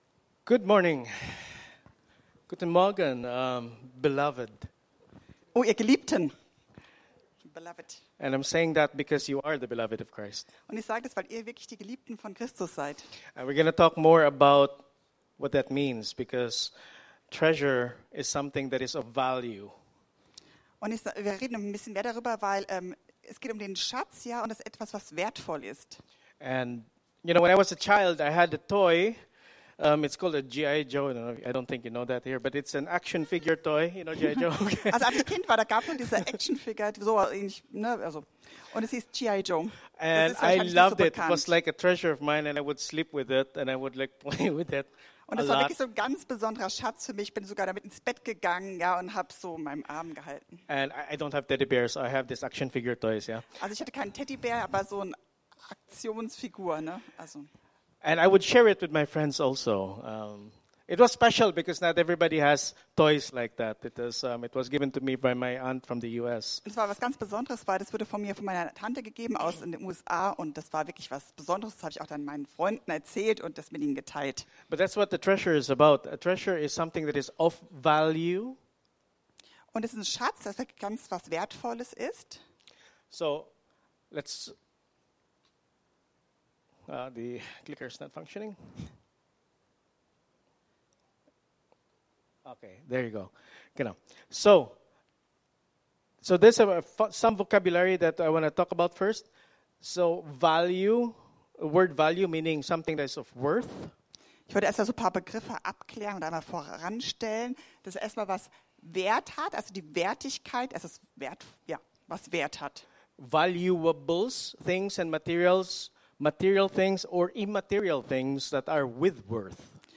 Aktuelle Predigten aus unseren Gottesdiensten und Veranstaltungen